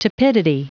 Prononciation du mot tepidity en anglais (fichier audio)
Prononciation du mot : tepidity